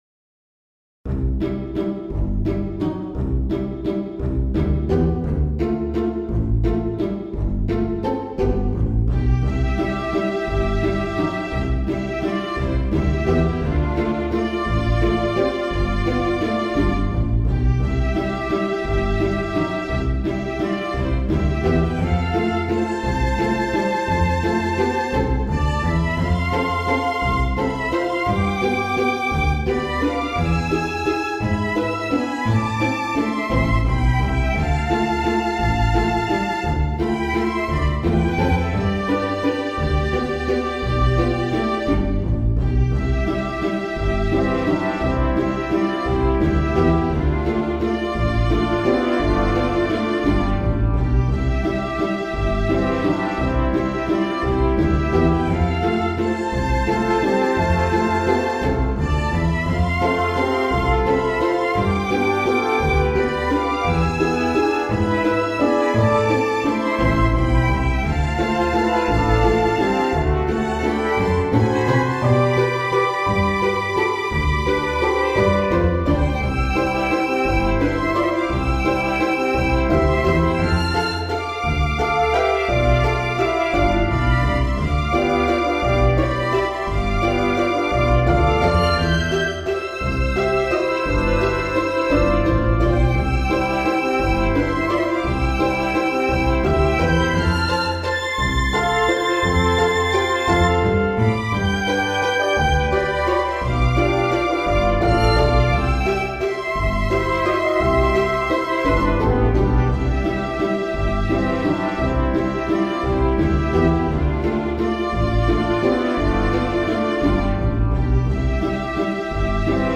クラシック明るい穏やか